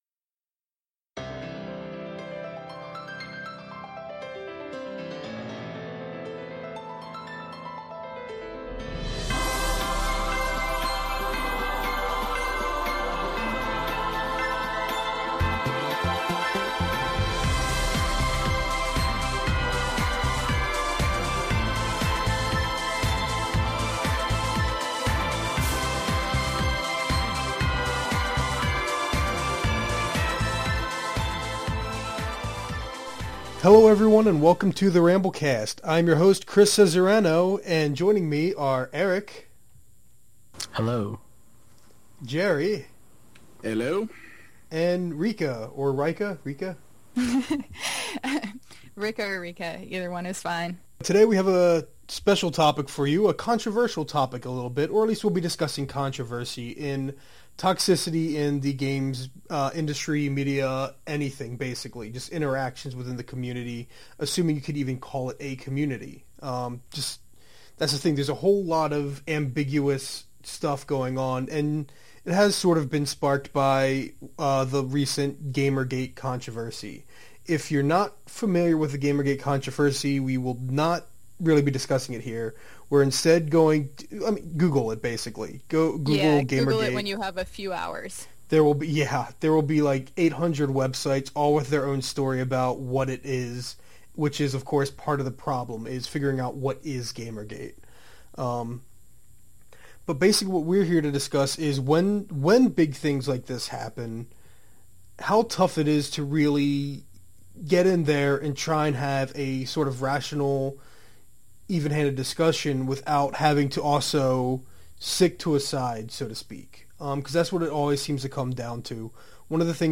I have a brand new microphone, but it seems like some experimentation and adjustment is still required. I had to do a lot of editing work this episode, and if you hear a buzzing when I’m speaking, well, that’s part of what I’m hoping to fix in the future.